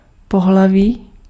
Ääntäminen
IPA : /ˈsɛks/